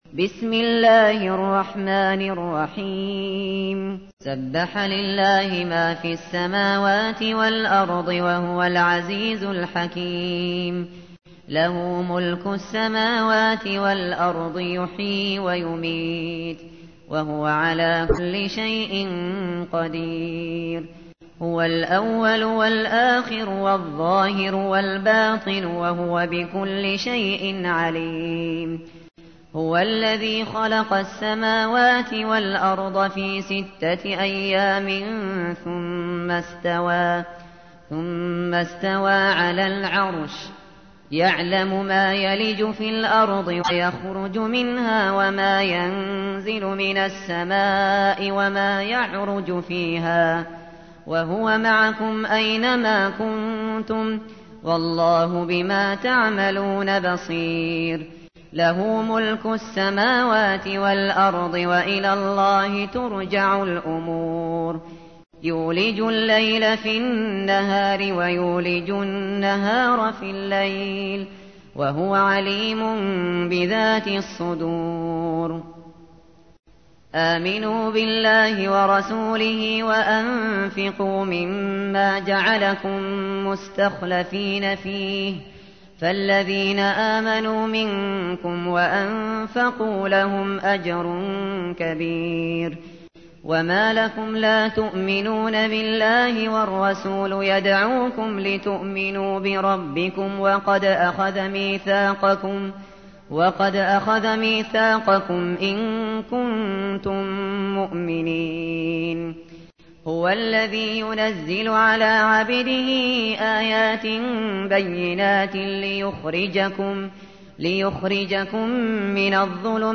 تحميل : 57. سورة الحديد / القارئ الشاطري / القرآن الكريم / موقع يا حسين